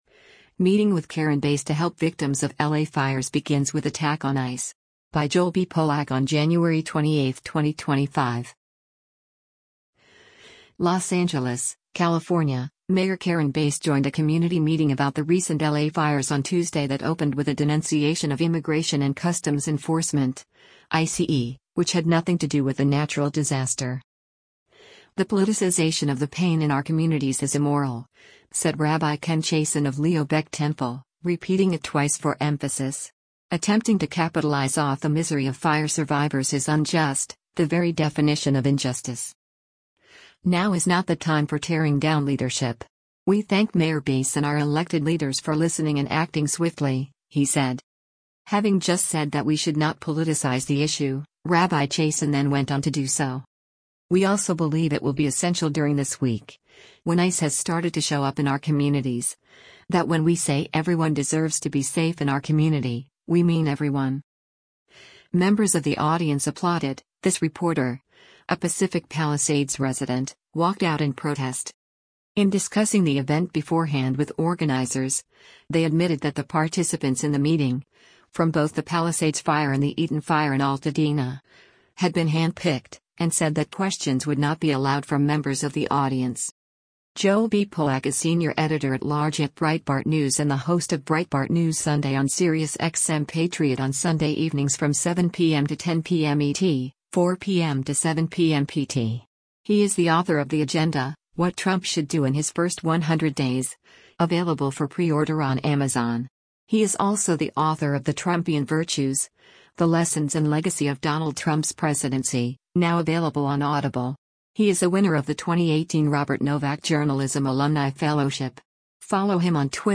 Members of the audience applauded; this reporter, a Pacific Palisades resident, walked out in protest.